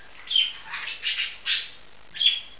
Wellensittich Songs: Musik für unsere Ohren
Hier jetzt einige "Songs" von unseren Sittichen, beim Schlummerstündchen und während des Spielens aufgenommen.
tschirp1.wav